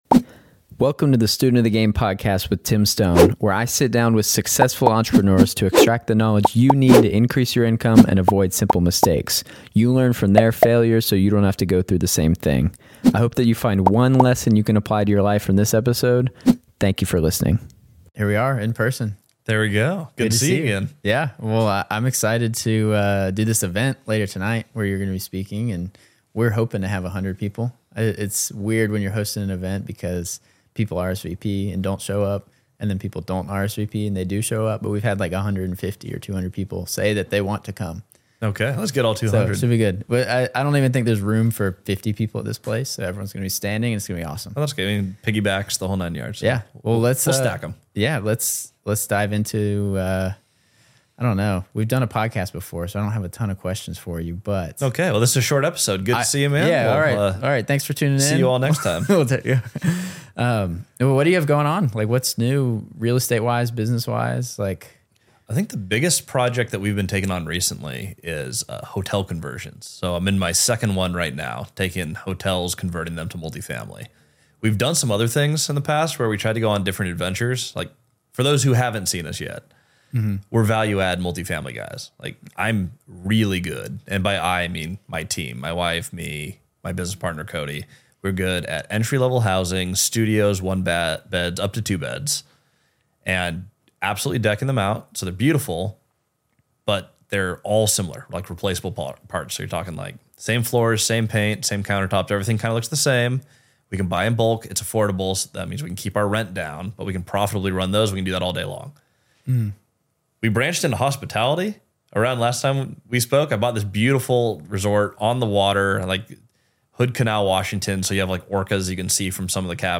" a captivating entrepreneurship podcast that interviews successful entrepreneurs, offering valuable insights and advice.